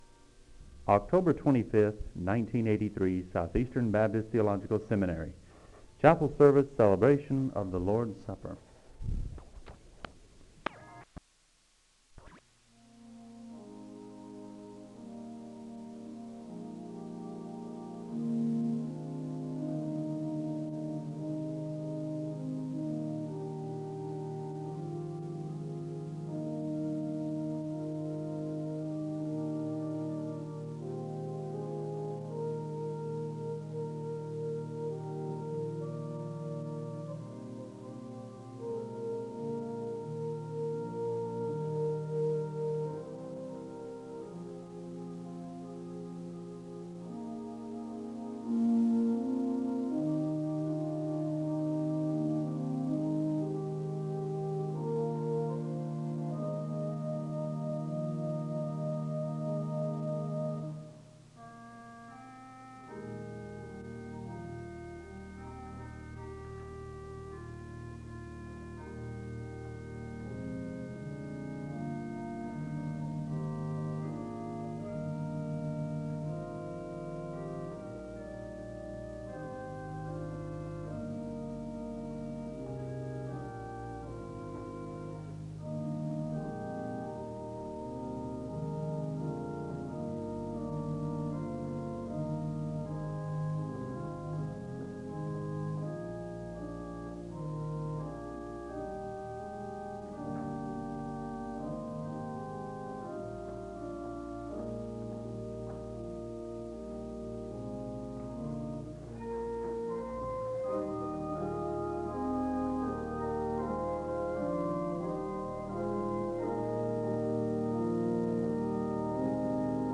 The service begins with organ music (00:00-02:47). The audience is led in a song of worship (02:48-06:33). The speaker leads in an invocation (06:34-07:11).
A bell choir performs a song of worship (10:16-15:08).
The choir sings a song of worship (23:19-26:14).
Organ music is performed (30:15-33:07).
Responsive worship